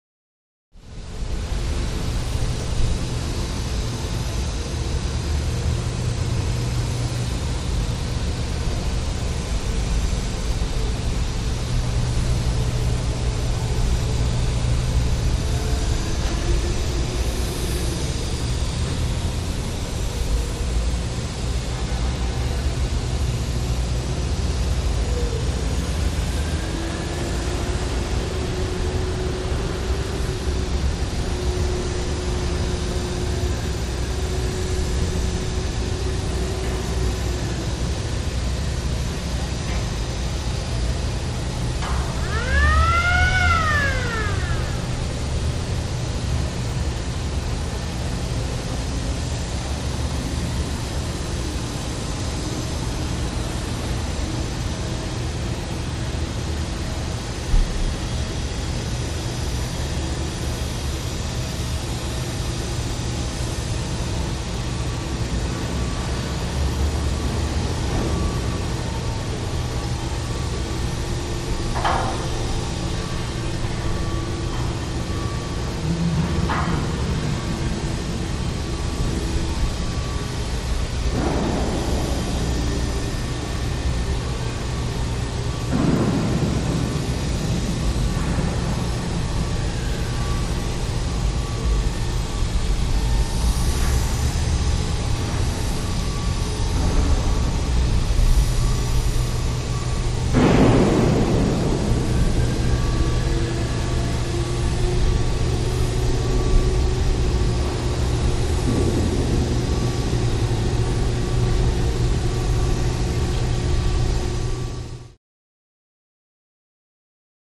Dam Interior
Dam Atmosphere; Exterior Dam Atmosphere. Light Background And Activity With Siren In Part.